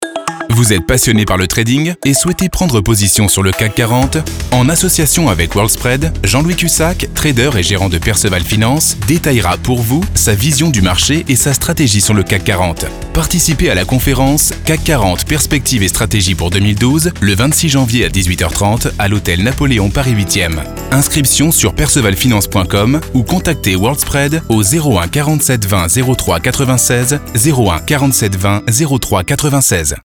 Voix off français grave posée jouée dynamique souriant home studio band annonce pub radio télé e learning
Sprechprobe: Werbung (Muttersprache):